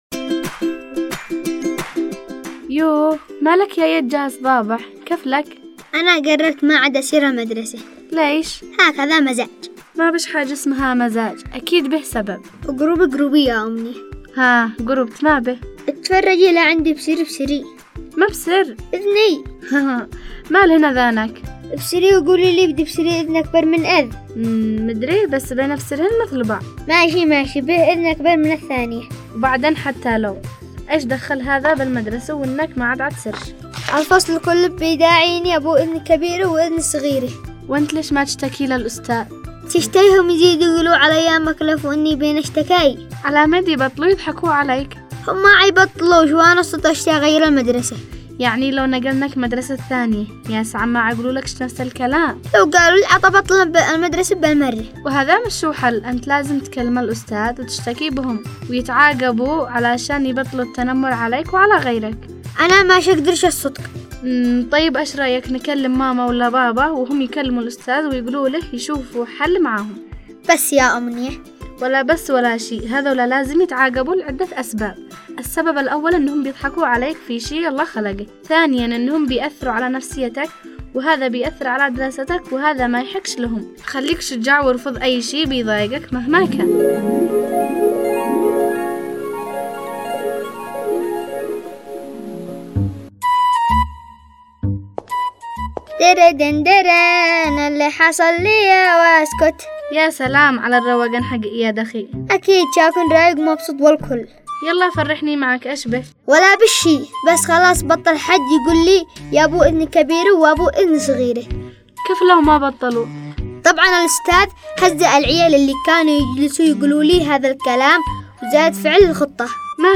برنامج انا واياد  تستمعون إليه عبر إذاعة صغارنا كل احد الساعة 1:00 ظهرا
حلقات دراميه تناقش مواضيع تخص الطفل والوالدين